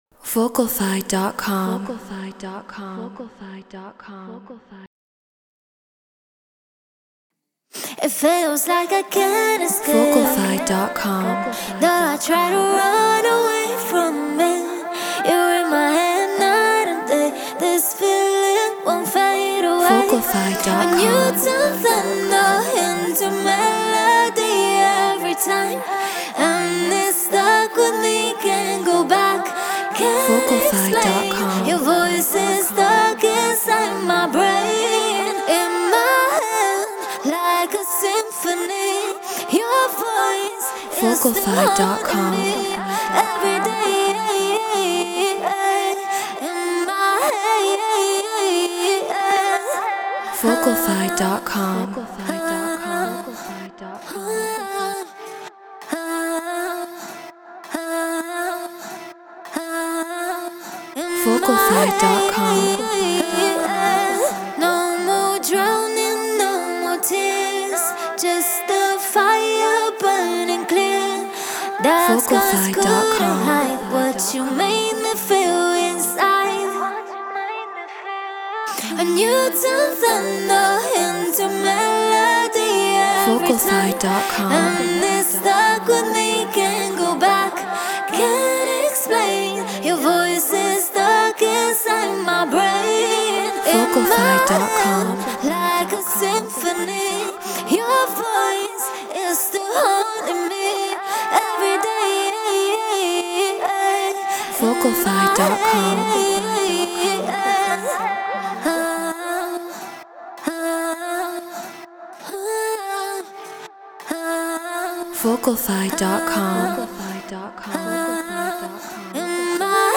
House 132 BPM Dmin